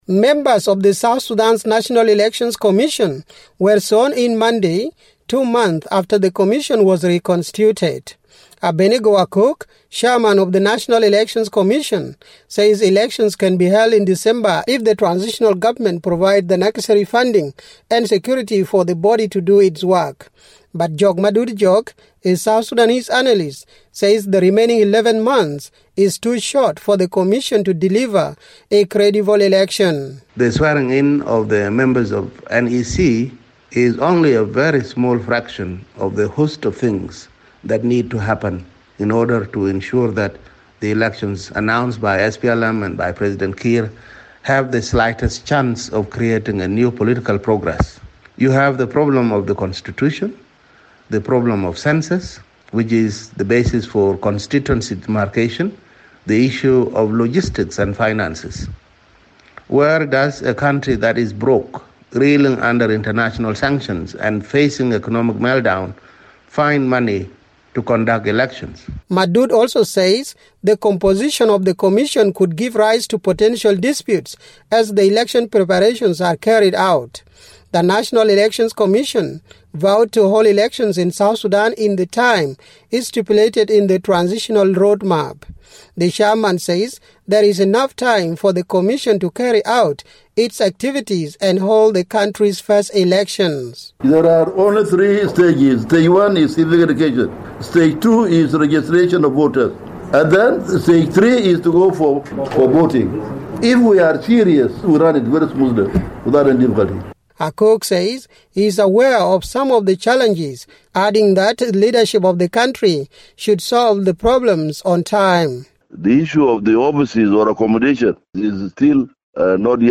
reports for VOA from Juba.